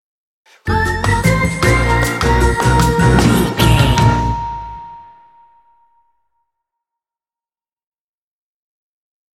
Uplifting
Aeolian/Minor
percussion
flutes
piano
orchestra
double bass
silly
circus
goofy
comical
cheerful
perky
Light hearted
quirky